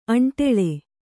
♪ aṇṭele